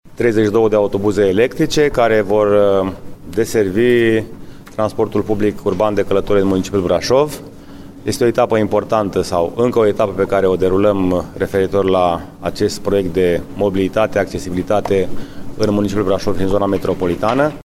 Primarul George Scripcaru: